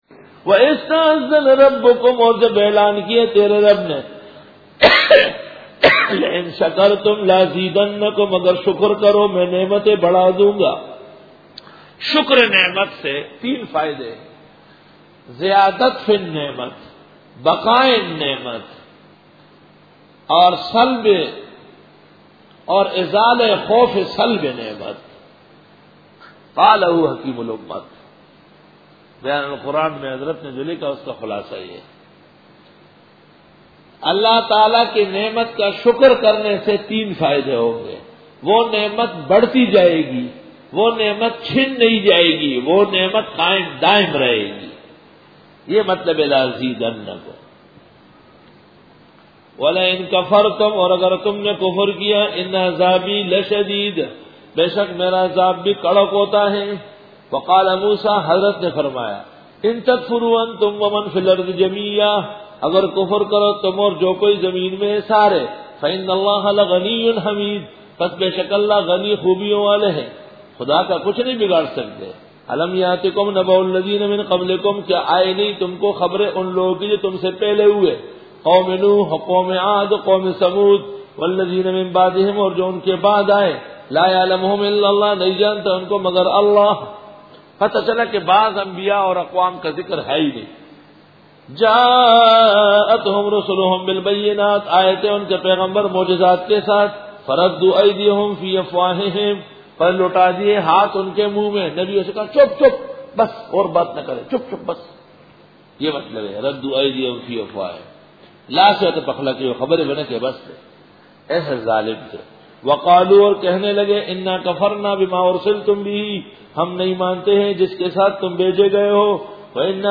Bayan
Dora-e-Tafseer 2004